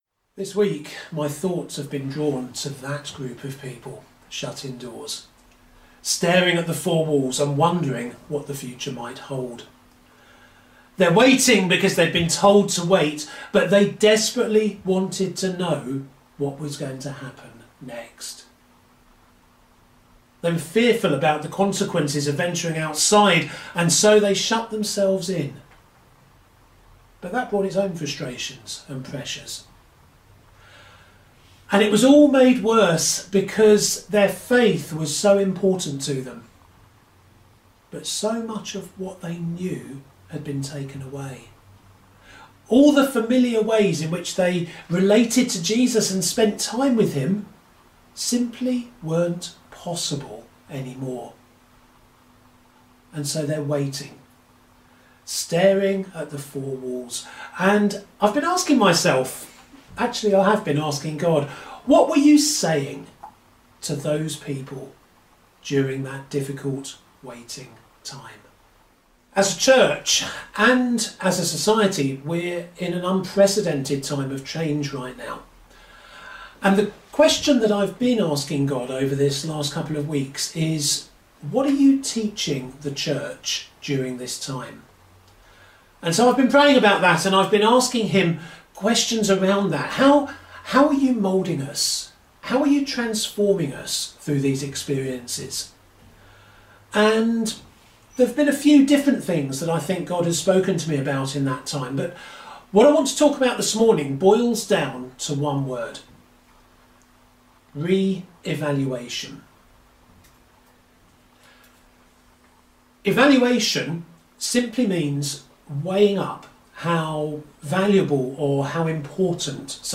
Sermon from Christmas Carol service 2020